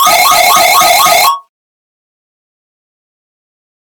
Screechy alarm
alarm alert beep bell design digital ding electronic sound effect free sound royalty free Sound Effects